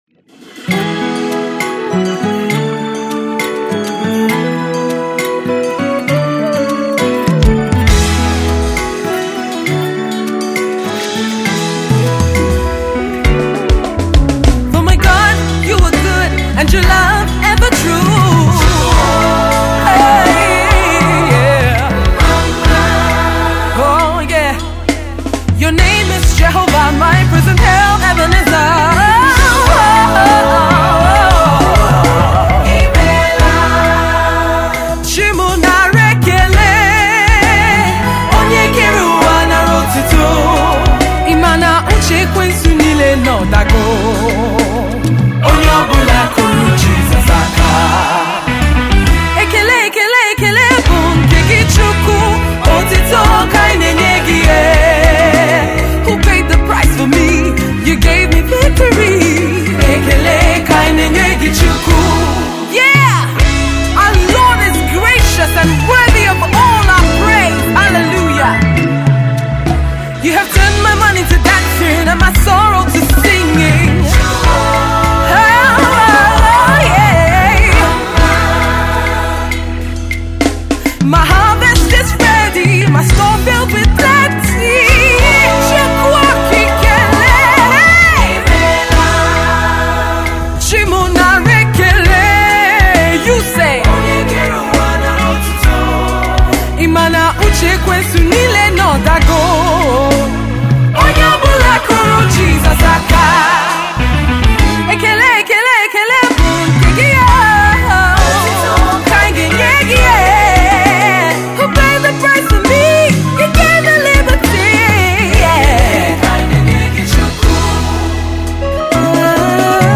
with a soar a way danceable single
Tags:    Gospel Music,    Naija Music